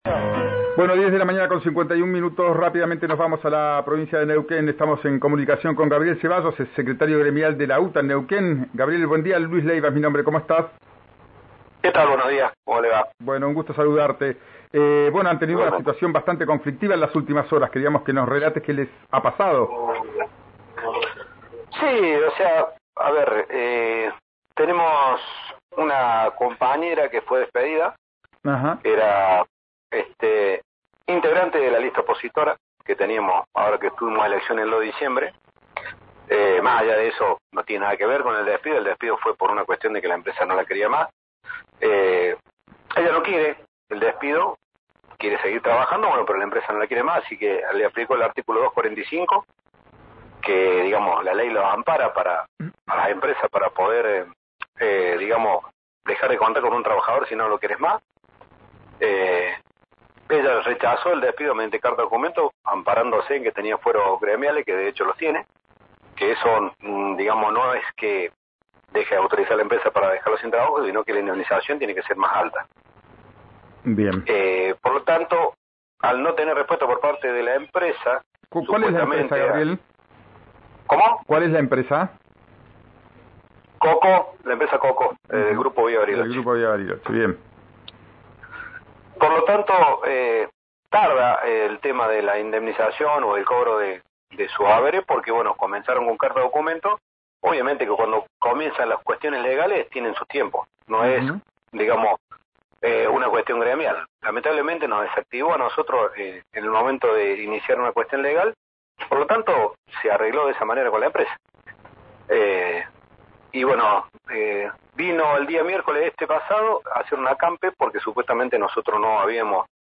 dialogó con RIO NEGRO RADIO y explicó resumidamente el punto de vista del gremio.